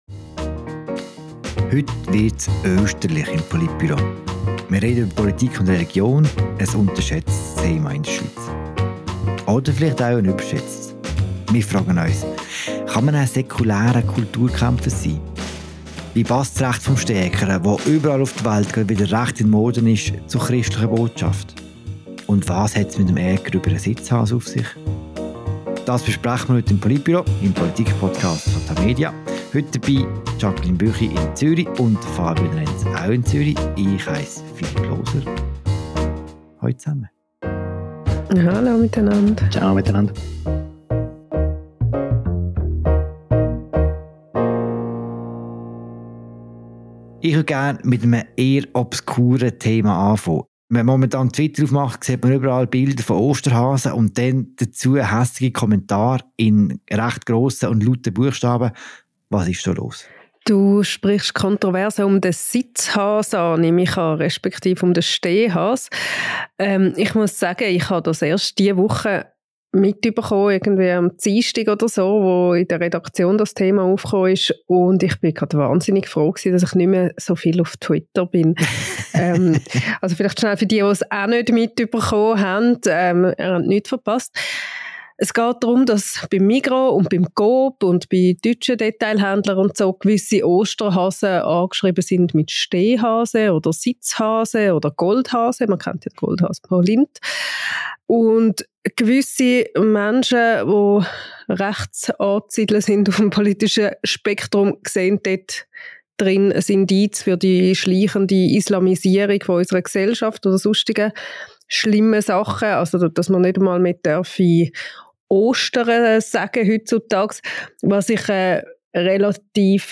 Ein Anlass für ein Gespräch über Politik und Religion in der neusten Ausgabe des Podcasts «Politbüro».